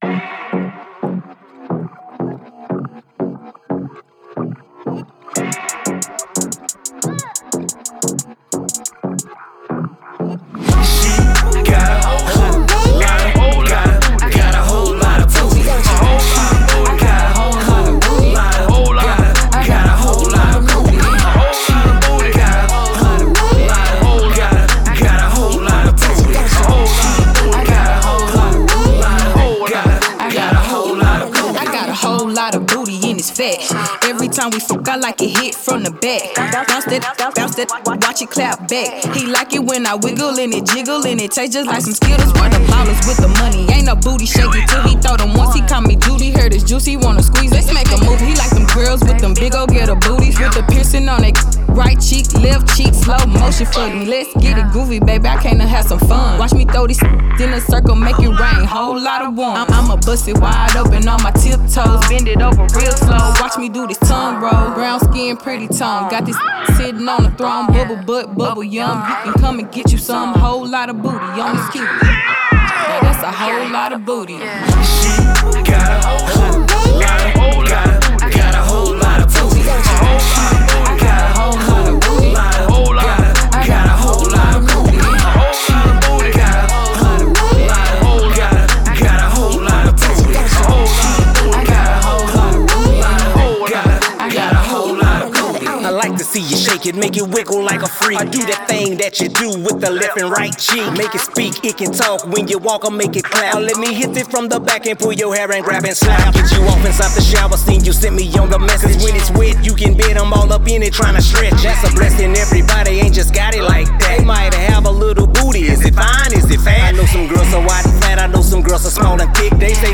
Description : Hot twerk song